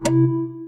big-sur-move-file.wav